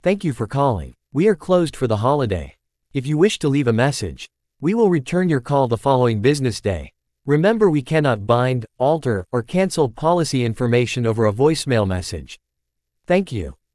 Generic Holiday Greeting
greeting-generic-holiday-2024.wav